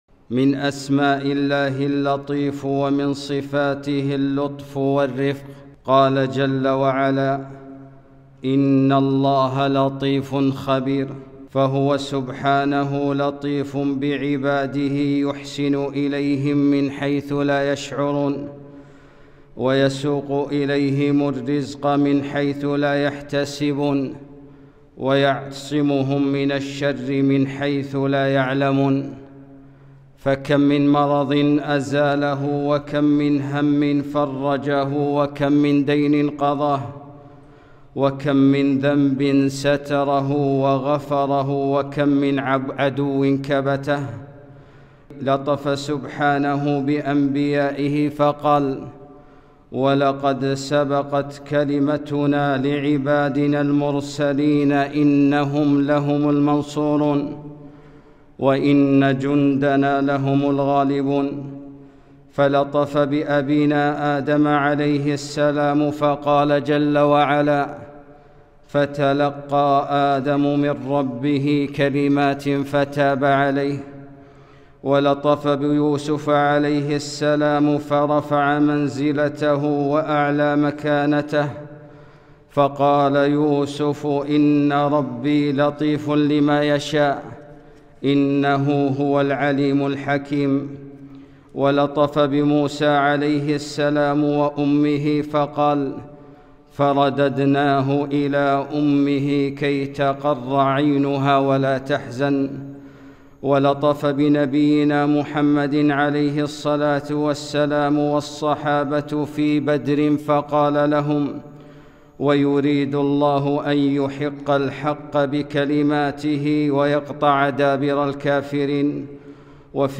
خطبة - الله لطيف بعباده